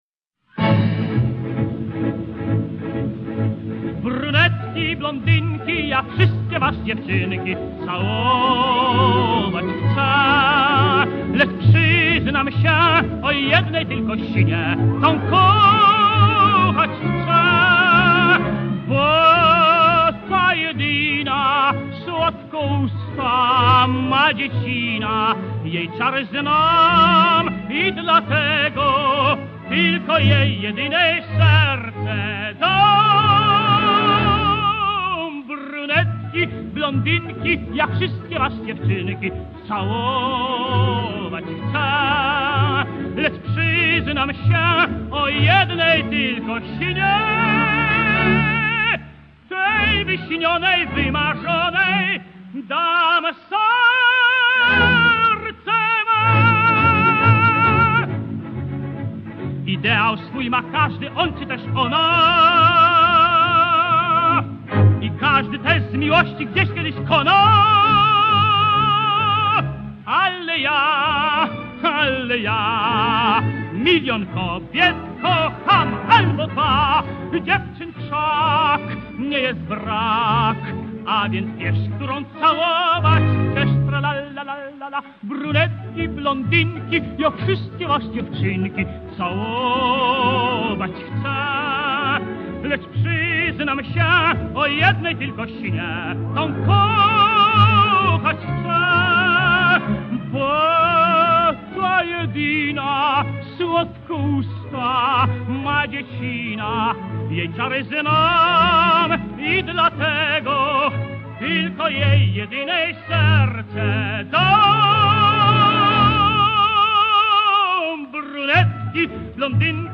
słynny tenor